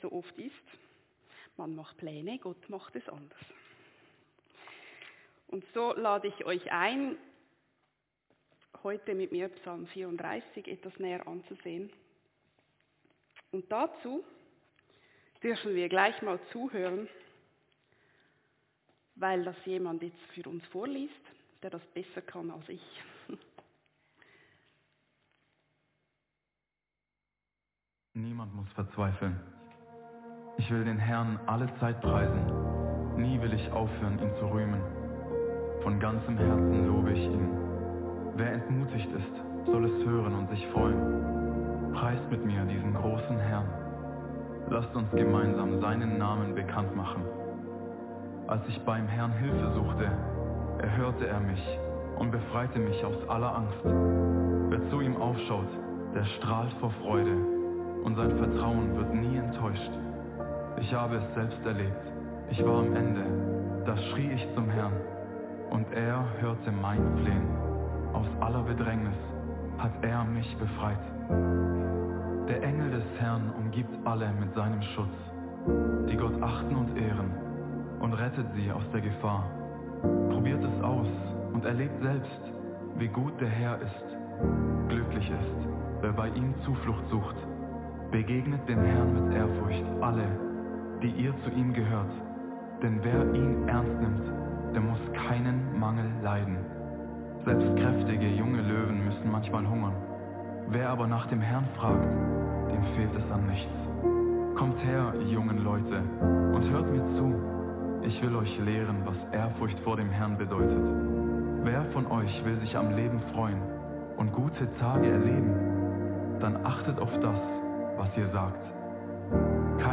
Predigt-27.4.25.mp3